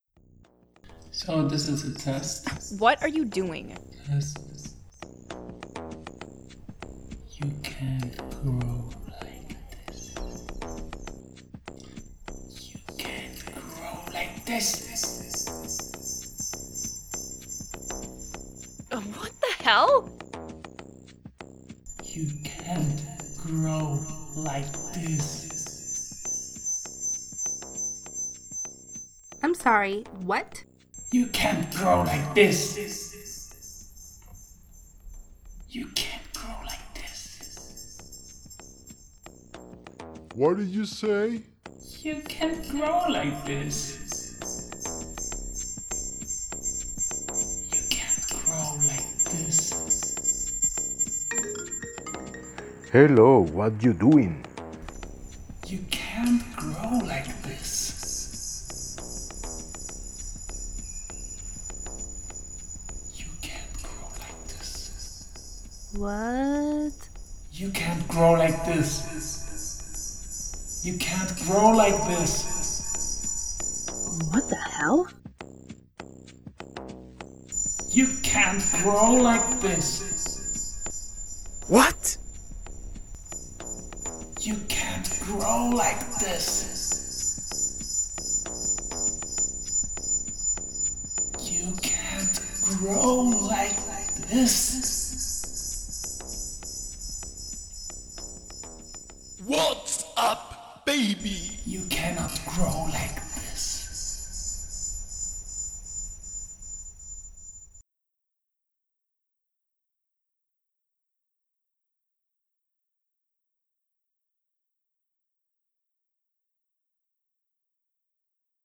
audio installazione, baseball cap, speaker, phone, cinematic gelatine (pink)
Sotto il cappello da baseball e´ posizionato un wireless speaker che riproduce in loop un file audio.